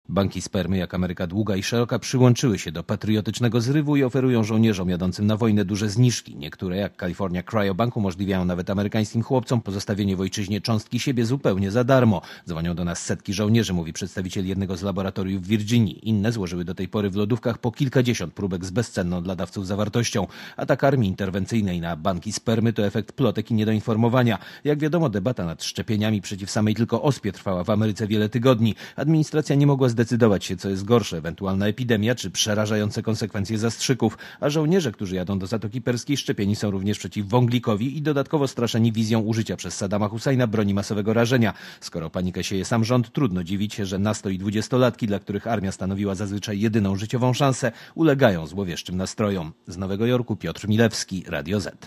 Posłuchaj relacji korespondenta Radia Zet (0,4 MB)>